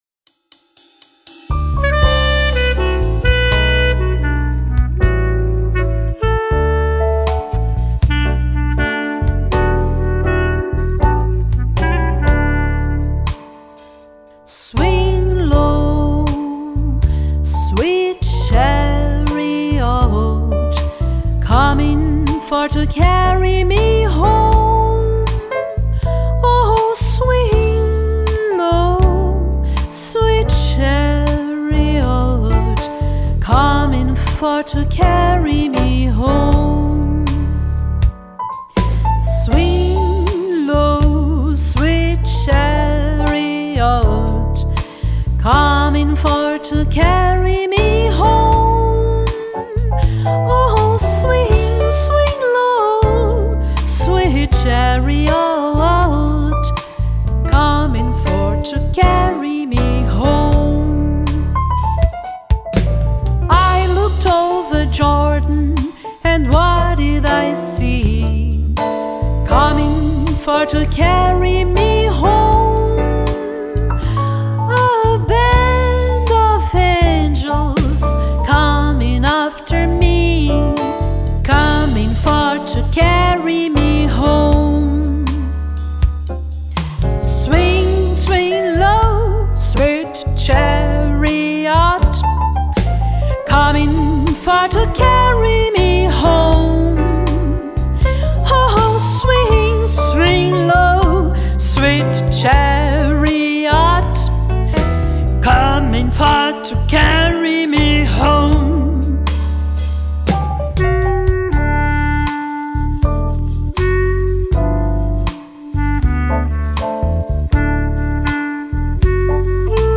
Negro Spiritual norte-americano